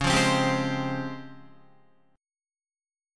DbM#11 chord